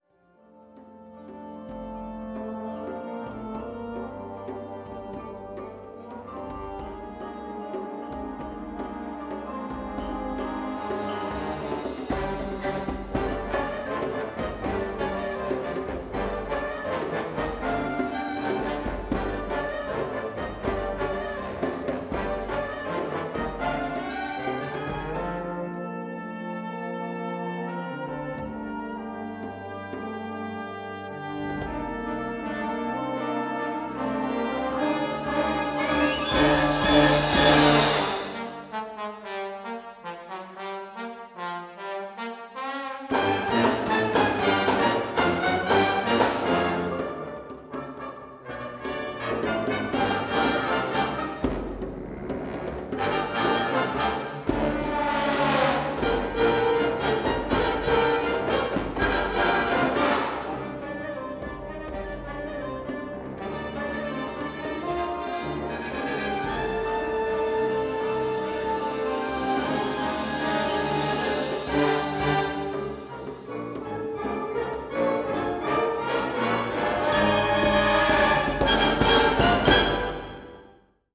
Tetrahedral Ambisonic Microphone
Recorded February 24, 2010, in the Bates Recital Hall at the Butler School of Music of the University of Texas at Austin.